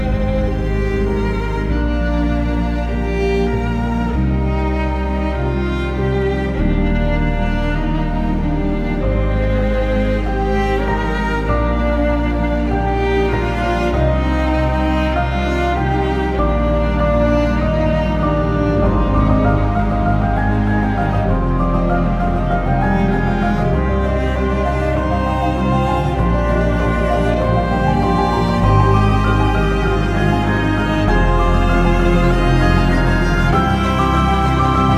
# Classical Crossover